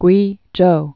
(gwējō, gwā-) also Kwei·chow (kwāchō)